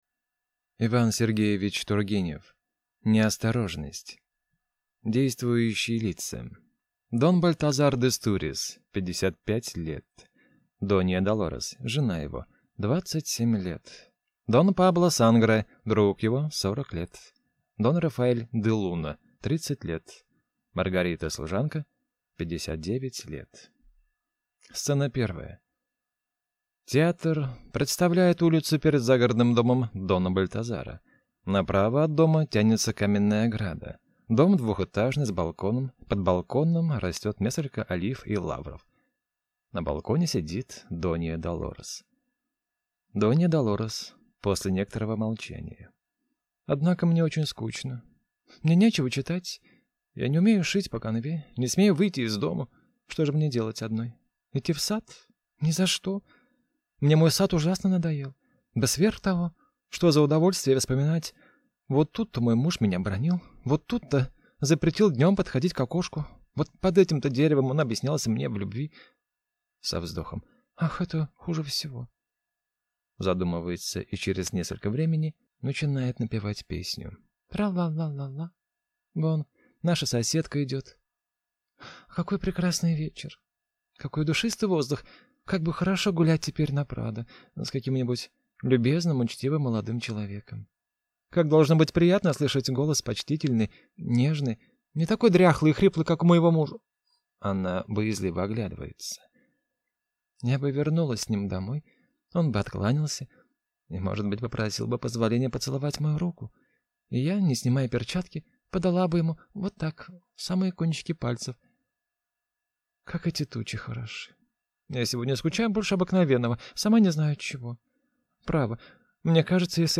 Аудиокнига Неосторожность | Библиотека аудиокниг